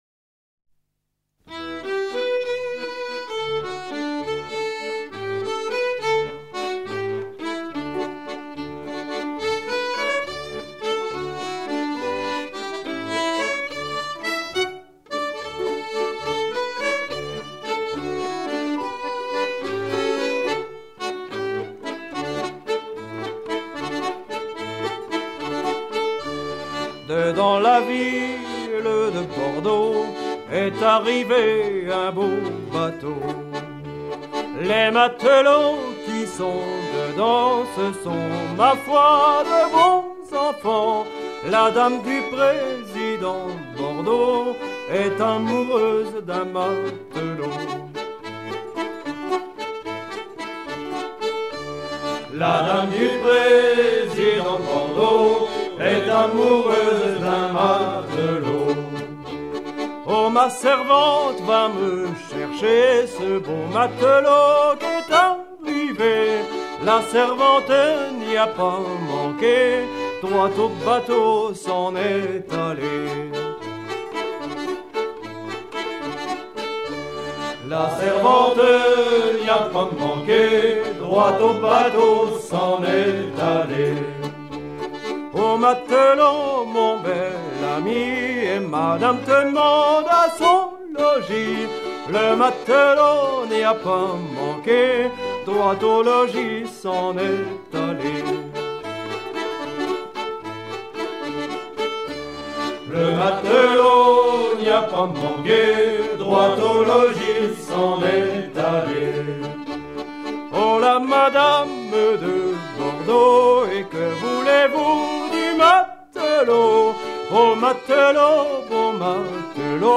Note Version adaptée par Ellébore d'une version francomtoise entendu du Grand Rouge à Lyon
Genre strophique
Catégorie Pièce musicale éditée